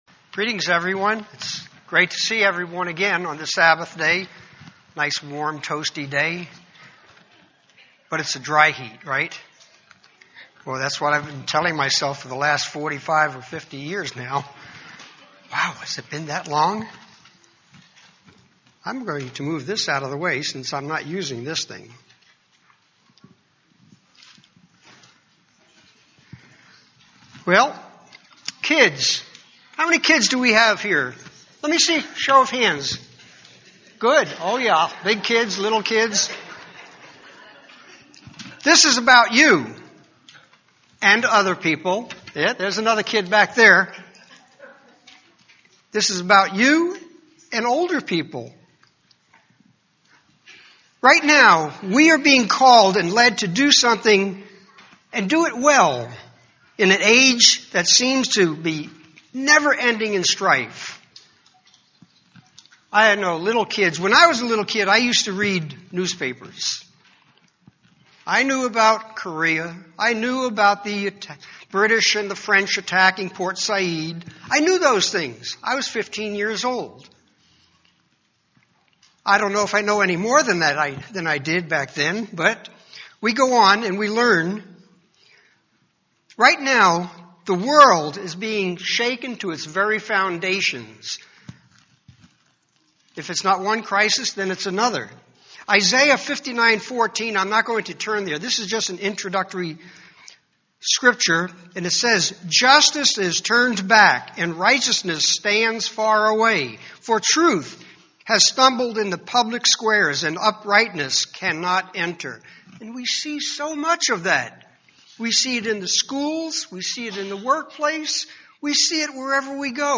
UCG Sermon Notes Introduction: Greetings Friends and welcome to our guests.